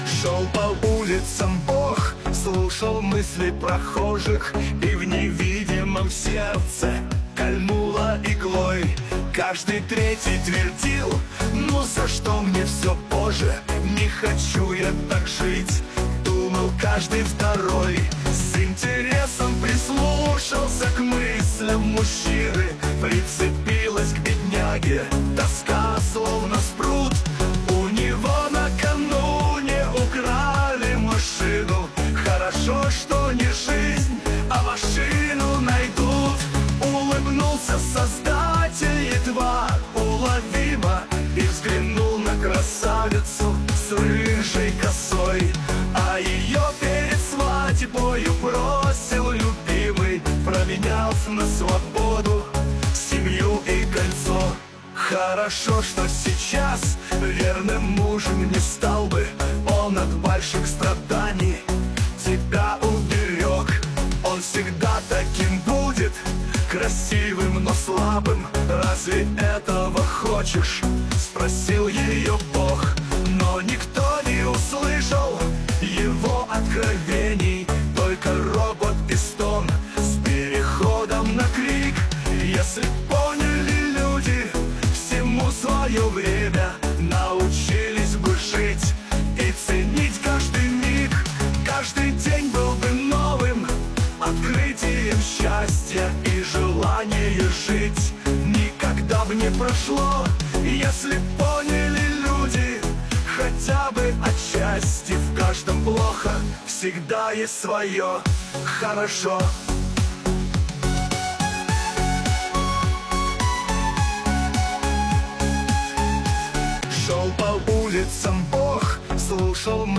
Песня создана с помощью нейросети Искусственного интеллекта